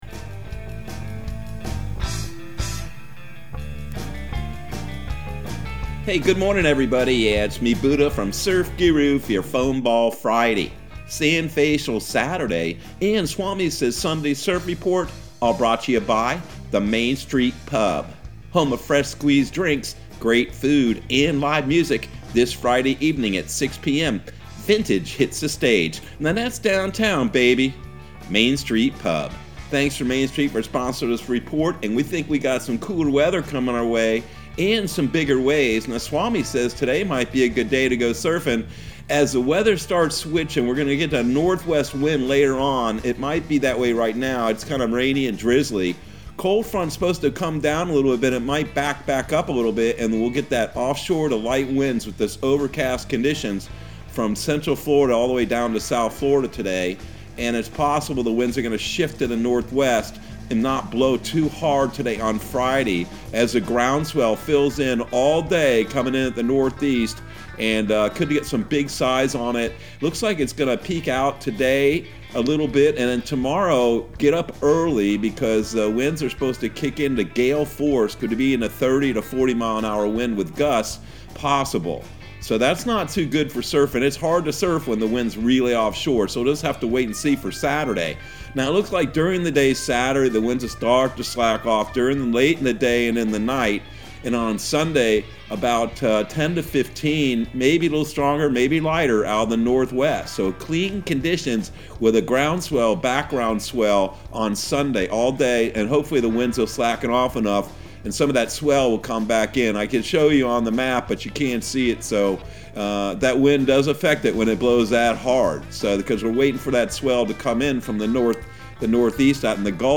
Surf Guru Surf Report and Forecast 01/28/2022 Audio surf report and surf forecast on January 28 for Central Florida and the Southeast.